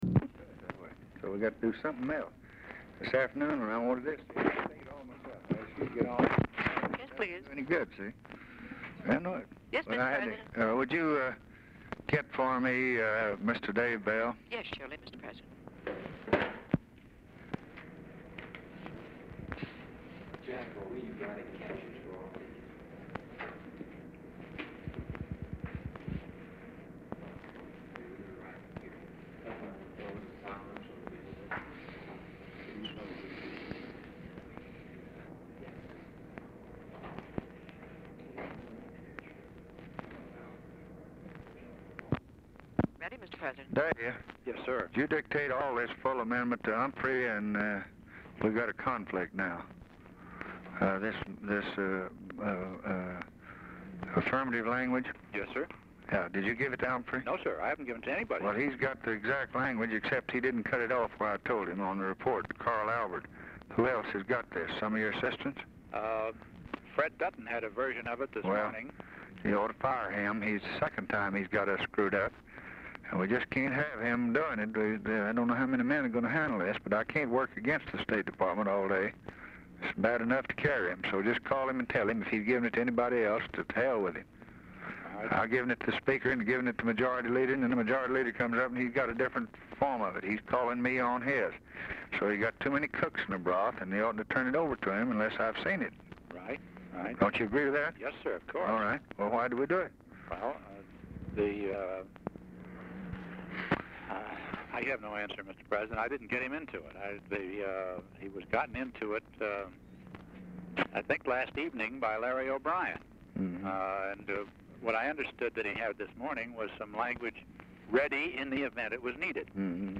Telephone conversation # 612, sound recording, LBJ and DAVID BELL, 12/20/1963, 8:05PM | Discover LBJ
Format Dictation belt
Location Of Speaker 1 Oval Office or unknown location
Other Speaker(s) TELEPHONE OPERATOR, OFFICE CONVERSATION
Specific Item Type Telephone conversation